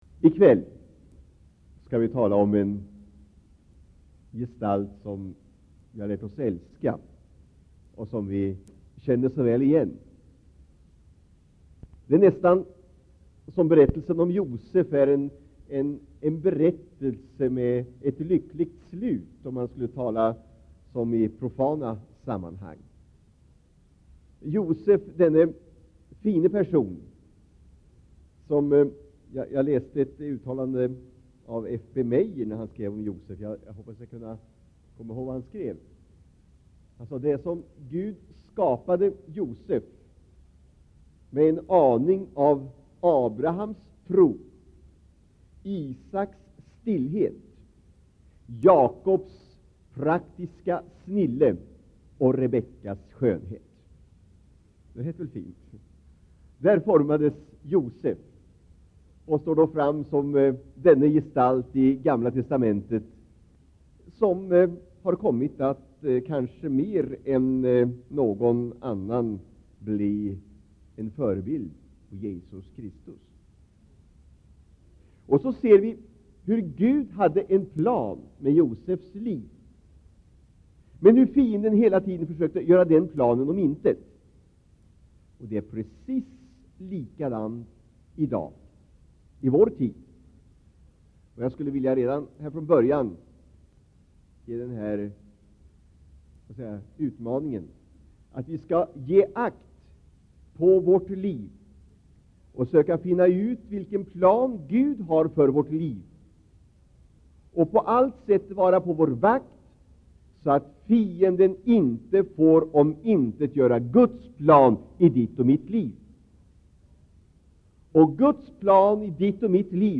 Inspelad i Citykyrkan, Stockholm 1984-02-15.